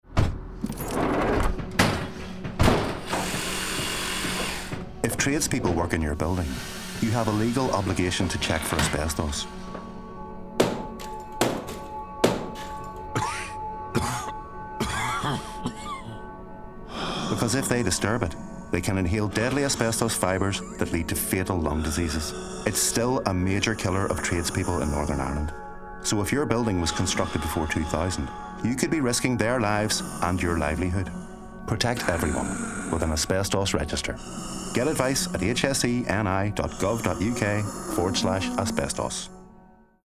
Radio Advert - Dutyholders.MP3